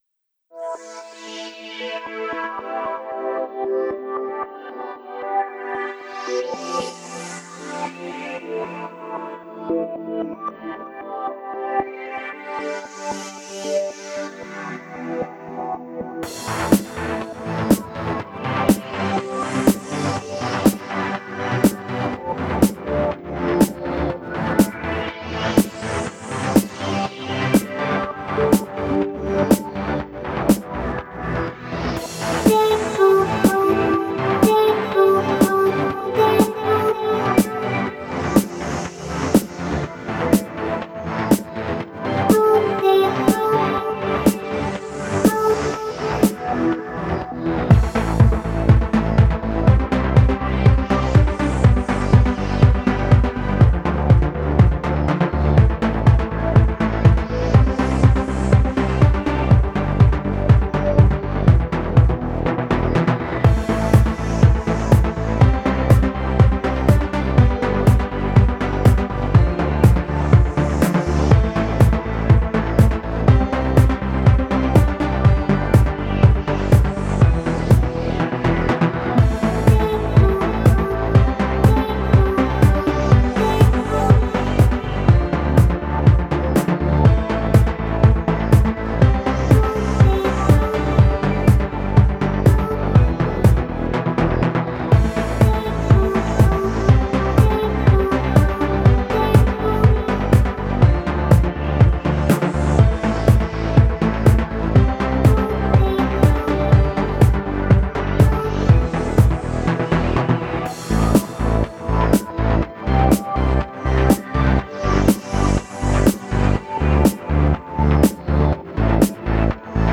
music / ELECTRO DEF